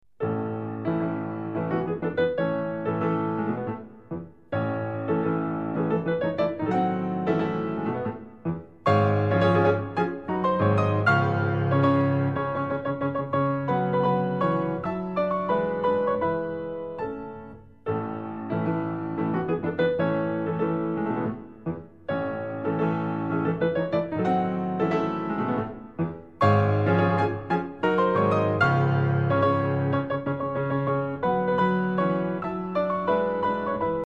Pianista
Recital pianistico di una allieva del Maestro.